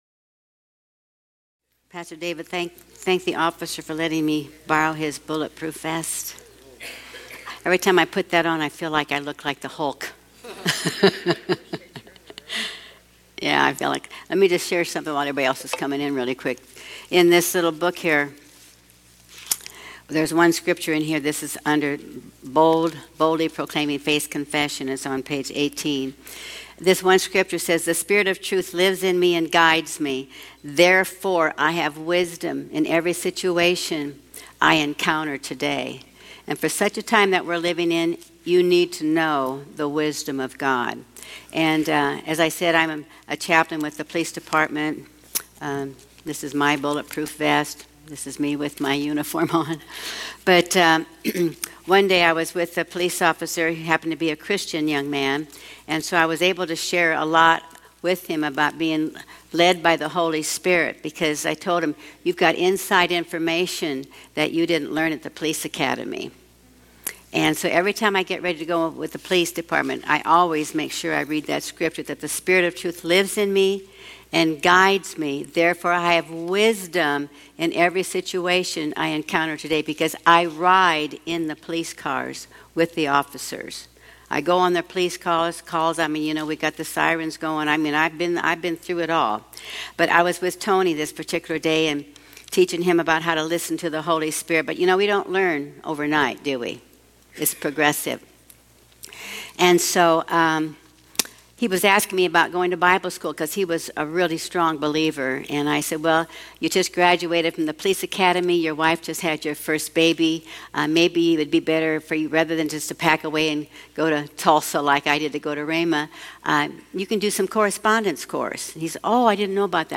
Sermons | Covenant Christian Church